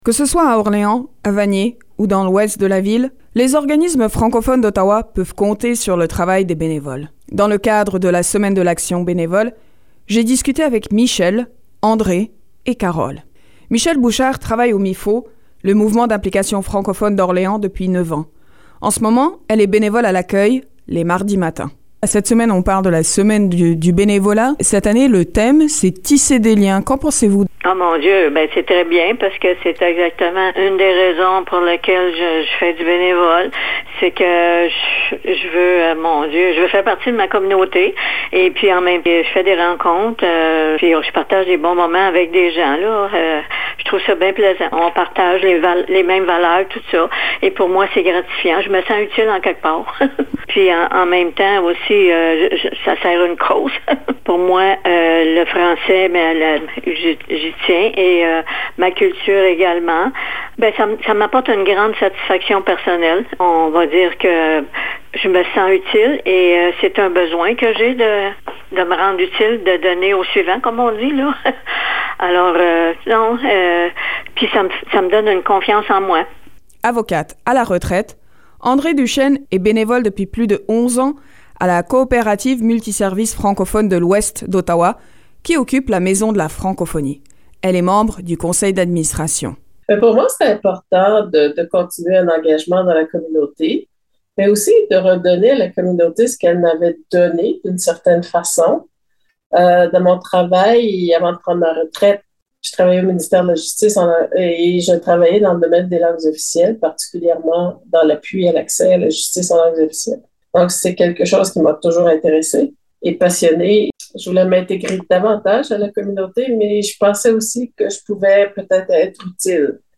Dans le cadre de la semaine de l’action bénévole (du 16 au 22 avril), j’ai fait des entrevues avec des bénévoles francophones de la capitale nationale.